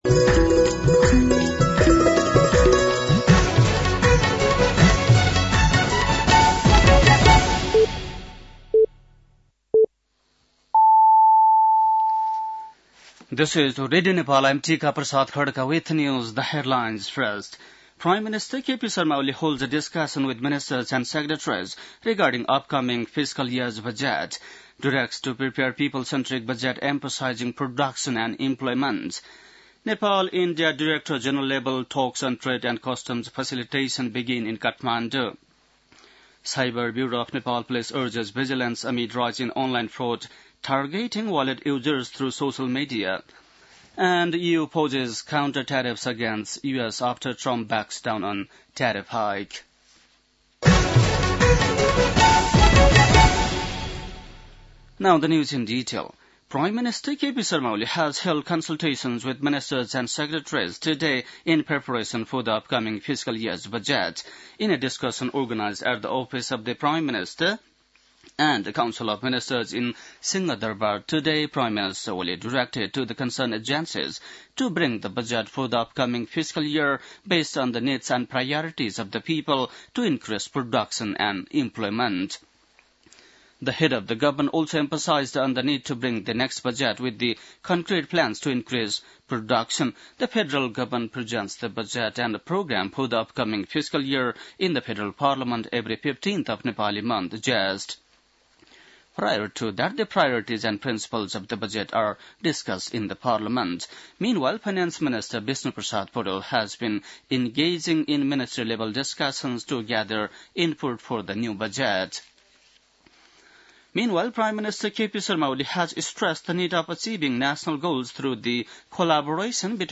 बेलुकी ८ बजेको अङ्ग्रेजी समाचार : २८ चैत , २०८१